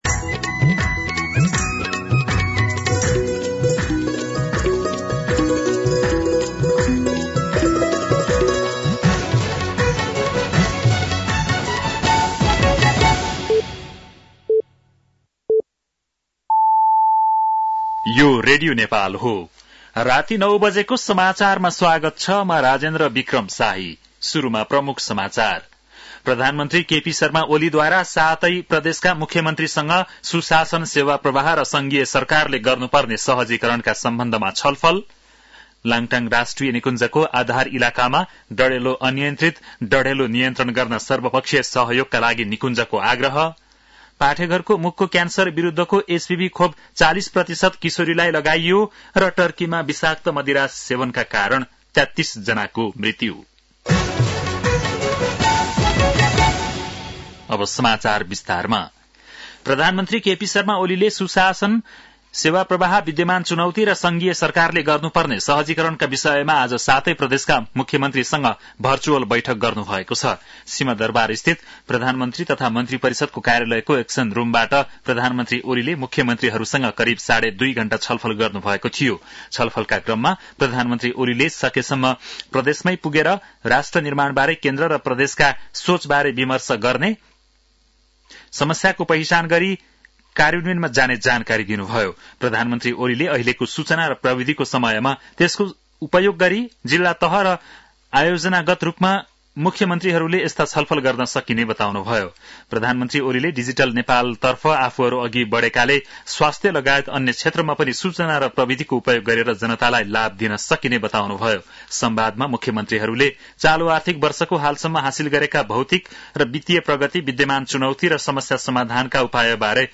बेलुकी ९ बजेको नेपाली समाचार : २६ माघ , २०८१
9-PM-Nepali-News-10-25.mp3